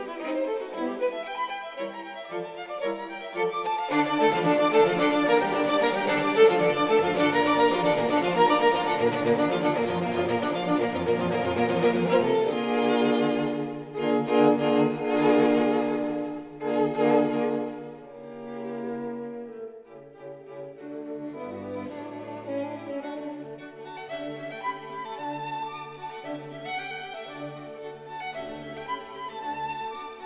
Streichquartette
String quartet, Hob.3/75, op. 76/1, sol majeur